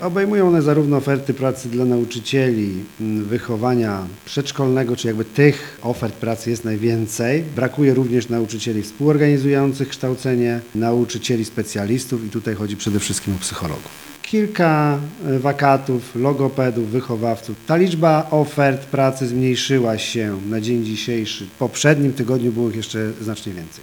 Ponad 100 wakatów w szkołach i przedszkolach województwa zachodniopomorskiego – tak wygląda początek nowego roku szkolnego w regionie. Najbardziej brakuje nauczycieli wychowania przedszkolnego, psychologów i logopedów – informuje kurator oświaty Paweł Palczyński.